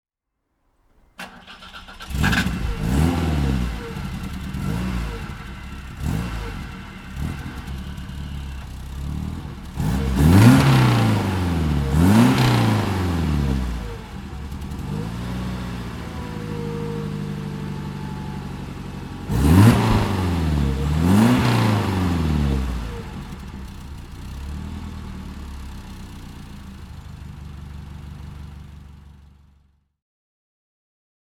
BMW Hurrican (1965) - Starten und Leerlauf
BMW_Hurrican_1965.mp3